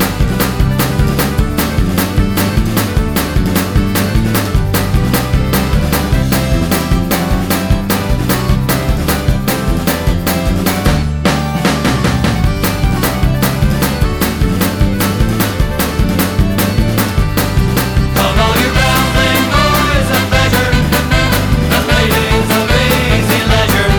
no Backing Vocals Irish 4:13 Buy £1.50